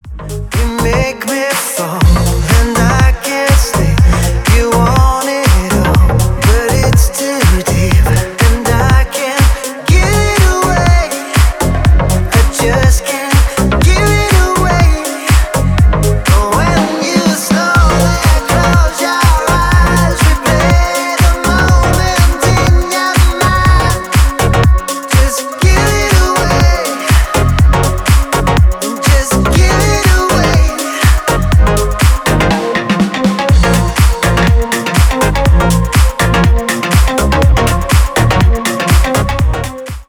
Ремикс # Поп Музыка # Танцевальные